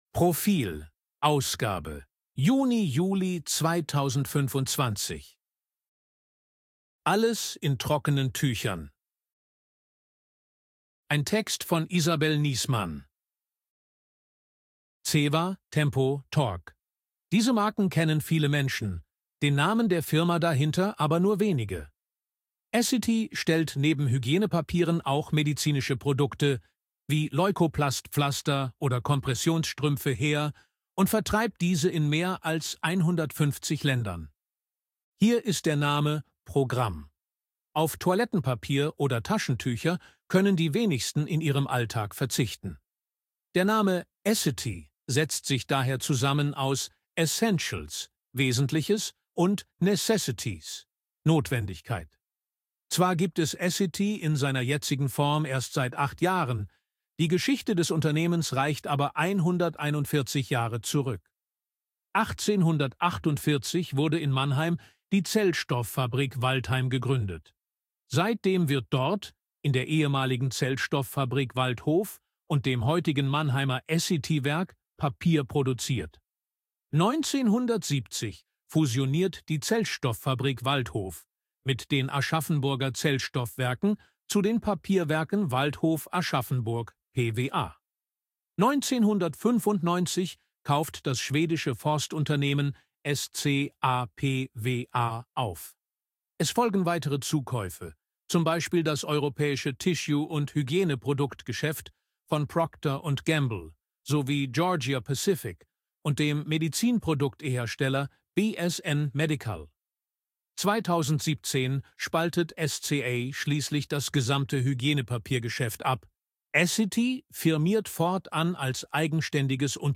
ElevenLabs_KI_Stimme_Mann_AG-Check.ogg